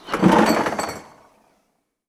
Abrir la puerta de un frigorífico con botellas
chocar
Cocina
Sonidos: Acciones humanas
Sonidos: Hogar